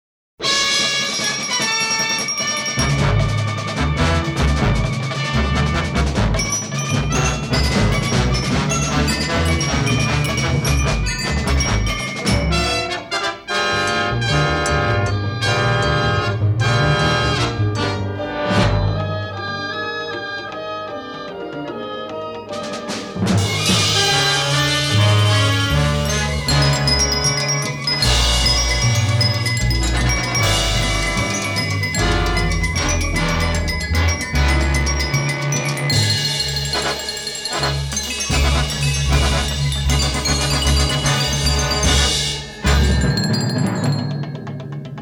are presented from clean-sounding mono 1/4" tape.